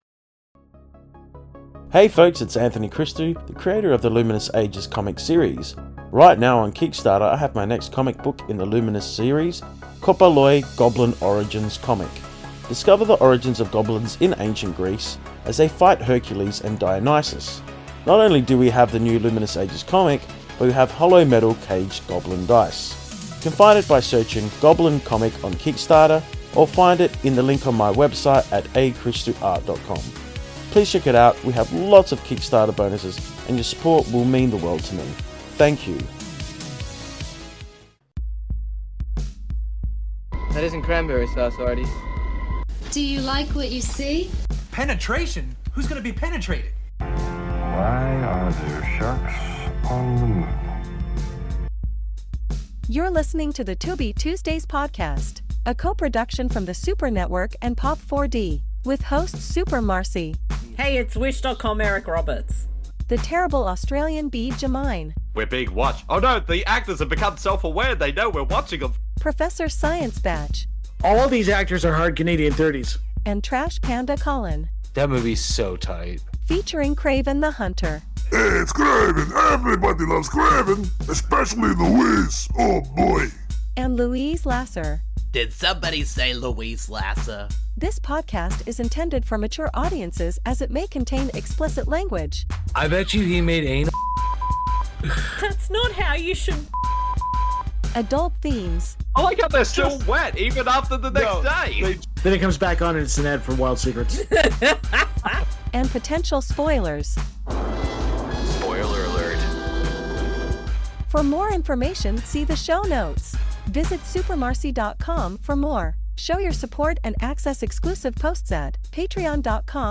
This podcast series is focused on discovering and doing commentaries/watch a longs for films found on the free streaming service Tubi, at TubiTV
Because we have watched the films on Tubi, it is a free service and there are ads, however we will give a warning when it comes up, so you can pause the film and provide time stamps to keep in sync.